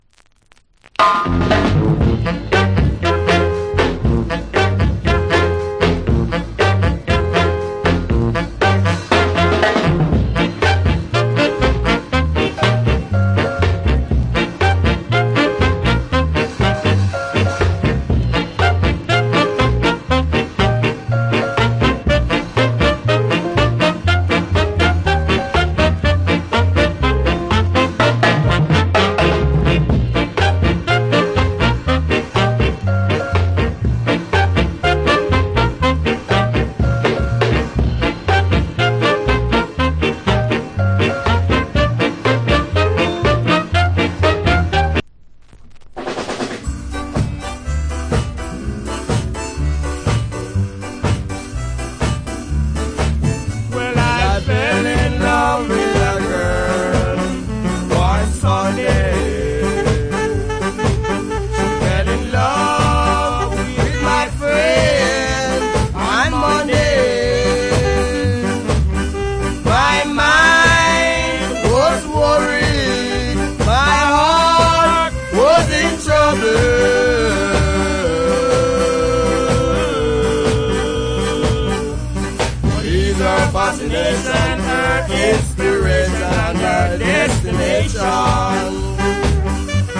Authentic Ska Inst.